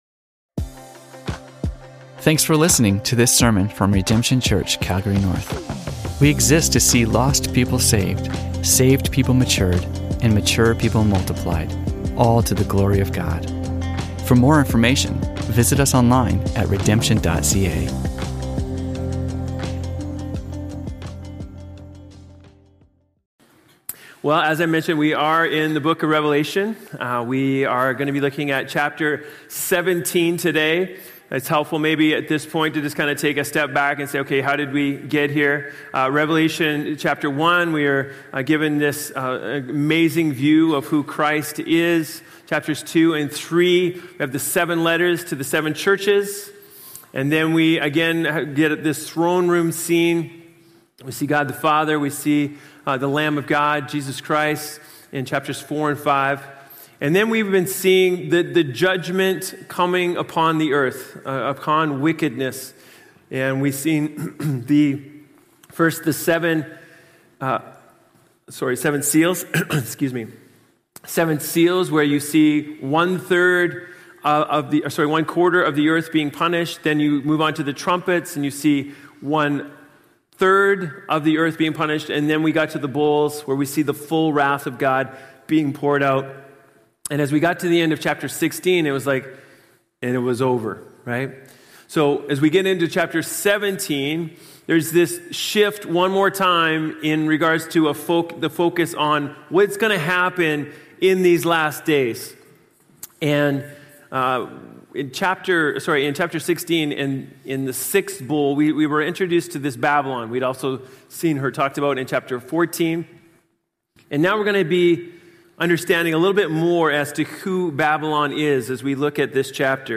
Sermons from Redemption Church Calgary North